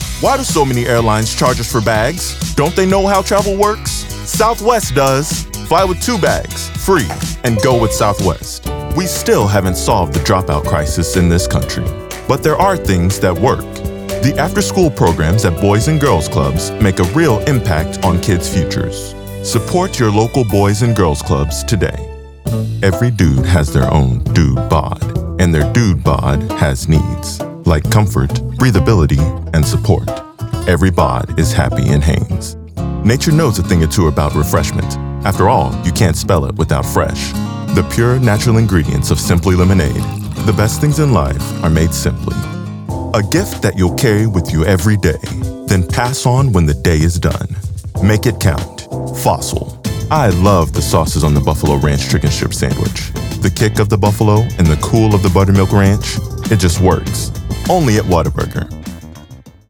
Professional Male Voice Actor